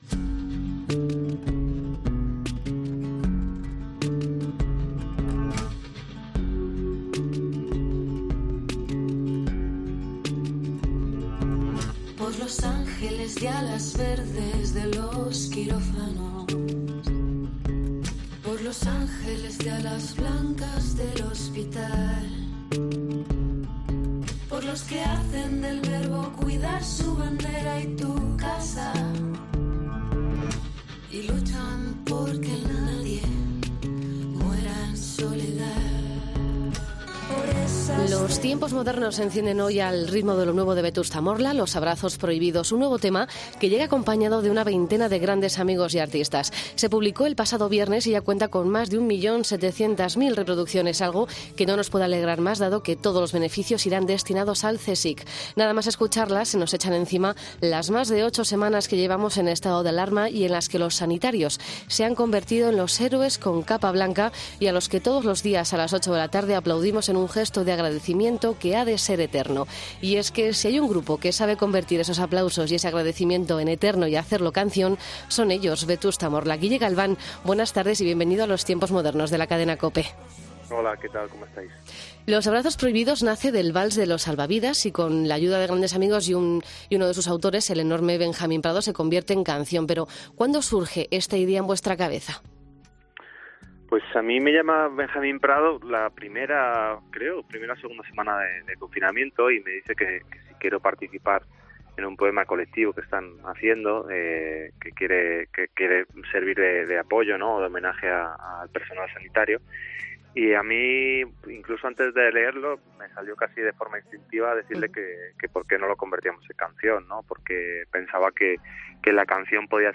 Entrevista a Guille Galván (Vetusta Morla) en los Tiempos Modernos